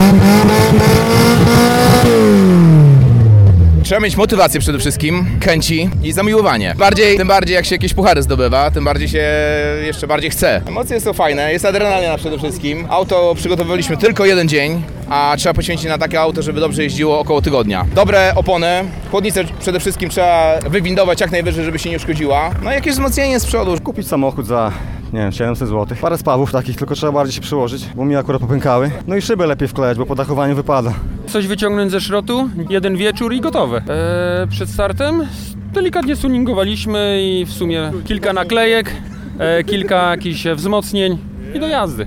O tym jak przygotować samochód do rajdów opowiedzieli Radiu 5 uczestnicy.